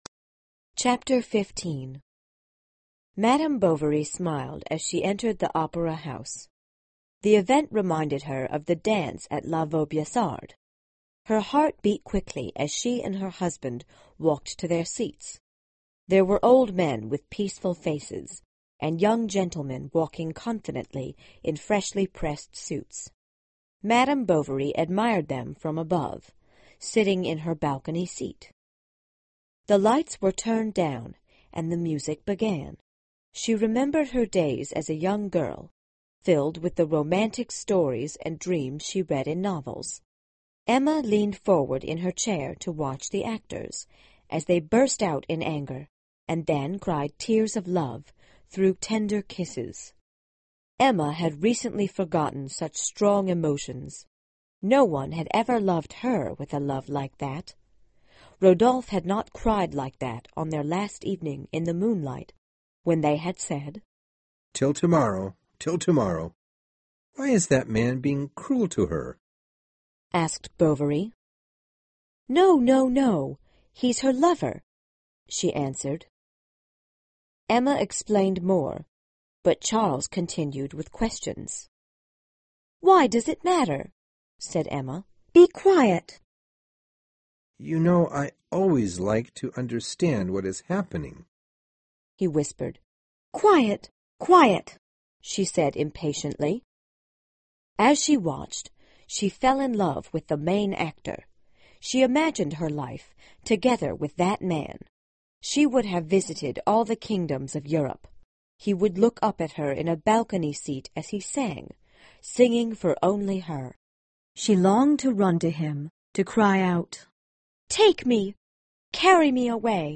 有声名著之包法利夫人 215 听力文件下载—在线英语听力室